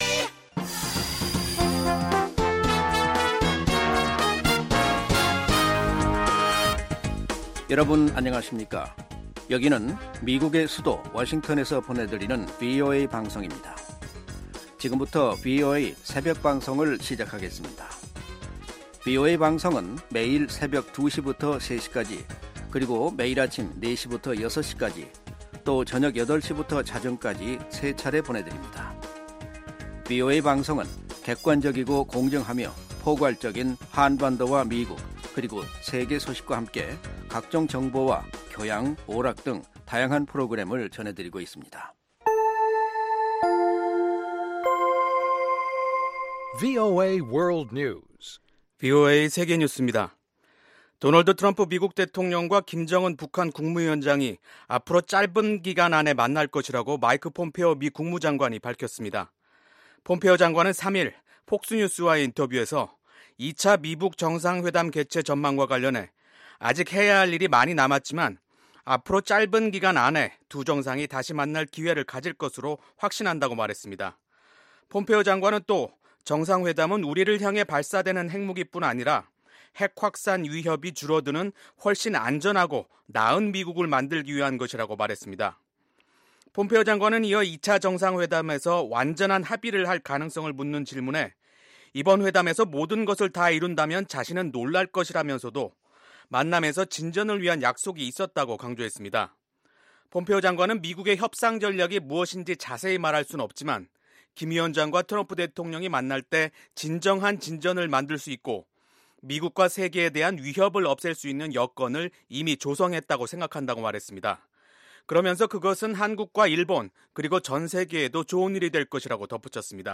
VOA 한국어 '출발 뉴스 쇼', 2019년 1월 5일 방송입니다. 3일 개원한 미국 의회에서 의원들은 북한 문제에 대해 외교를 통한 해결을 지지한다면서도 북한이 구체적인 비핵화 조치를 취할 때까지 제재는 계속돼야 한다는 초당적인 입장을 보였습니다. 북한이 미사일 시험 발사를 하지 않고도 핵심 역량을 개선시킬 수 있다고 전문가들이 지적했습니다.